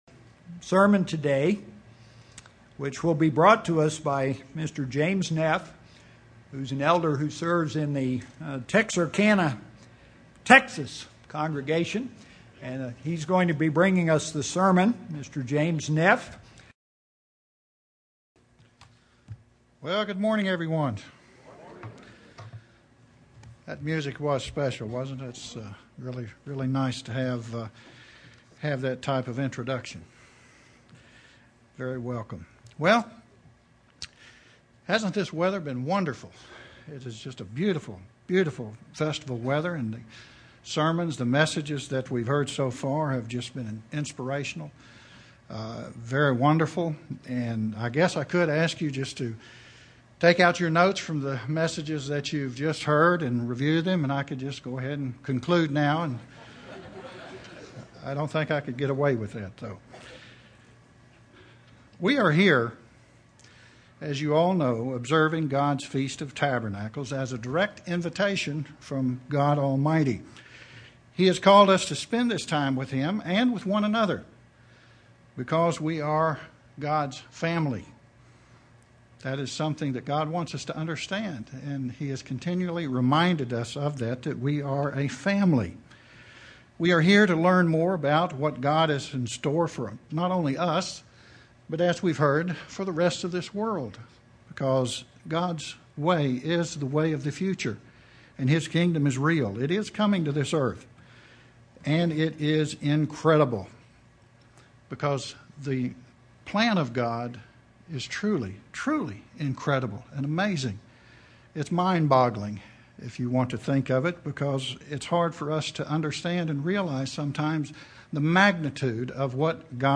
This sermon was given at the Branson, Missouri 2012 Feast site.